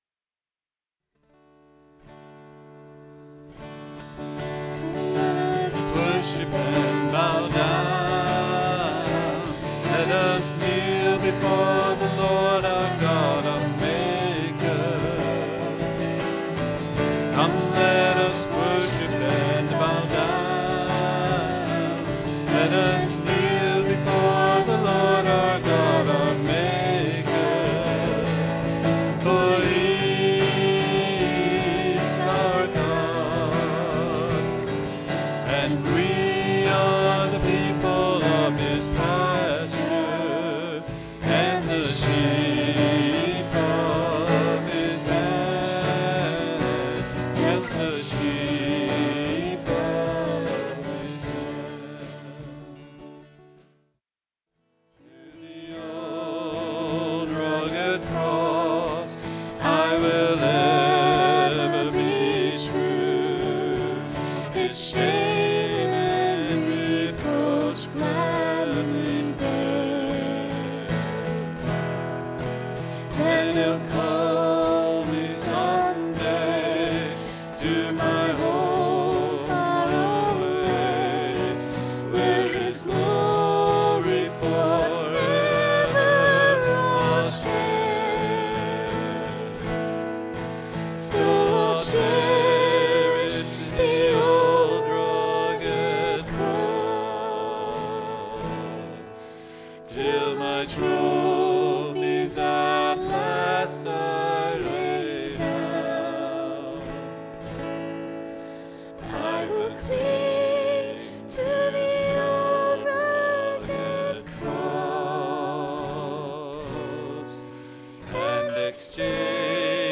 PLAY The Way of the Cross, Part 1, February 19, 2012 Scripture: Luke 9:18-23. Message given
at Ewa Beach Baptist Church